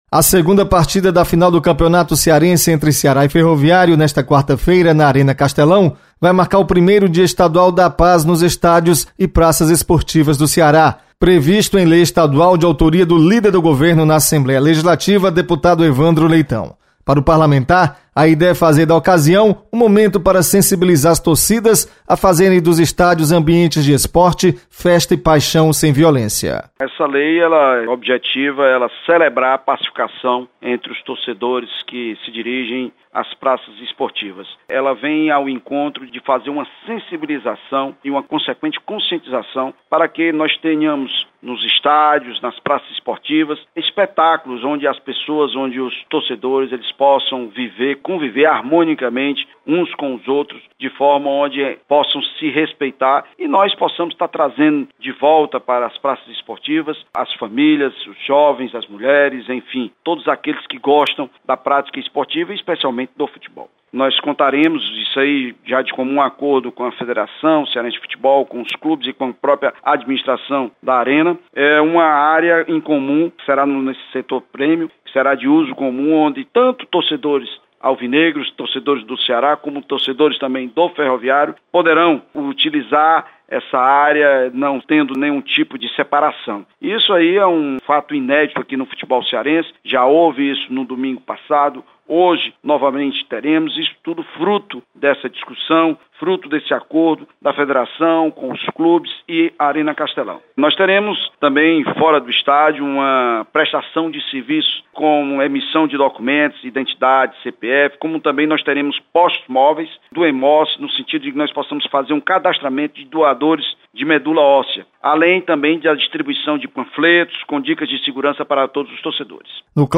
Deputado Evandro Leitão conclama torcedores para buscar a paz nos estádios. Repórter